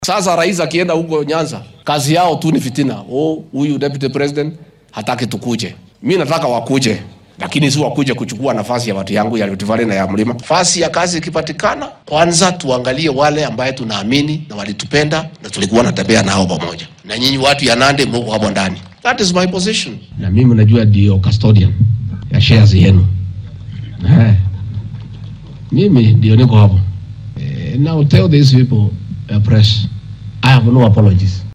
Ku xigeenka madaxweynaha dalka , Rigathi Gachagua ayaa sheegay inuu ku adkeysanaya oo uunan marnaba raaligelin ka bixin doonin hadalkiisi ahaa in saamiga dowladeed ee isbeheysiga Kenya Kwanza lagu qaybsanaya taageeradii la siiyay madaxweyne William Ruto. Xilli uu ku sugnaa deegaanka Kapsabet ee ismaamulka Nandi ayuu xusay inuunan jirin wax khilaaf ah oo kala dhexeya madaxweynaha wadanka balse ay jiraan shakhsiyaad isku dayaya inay fidno ka dhex abuuraan.